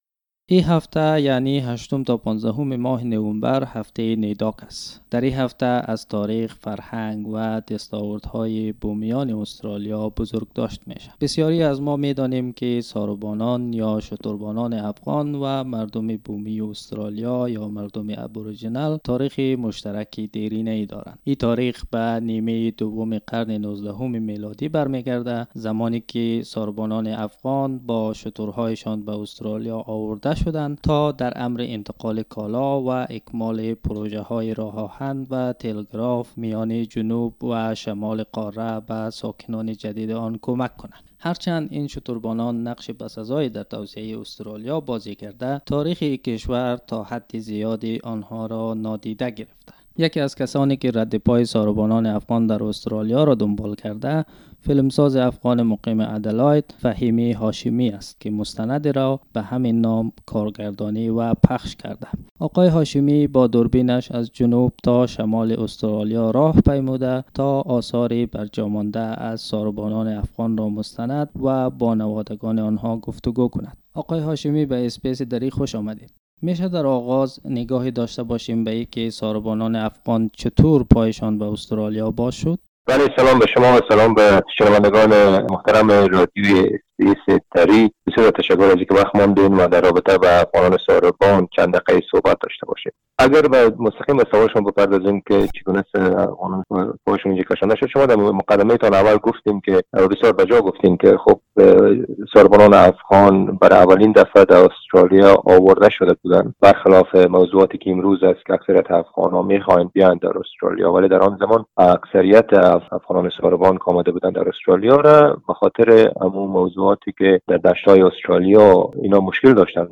The interview is in the Dari language When they first set foot on Australian soil with their camels 160 years ago, a group of Afghan cameleers were supposed to do their job and return home.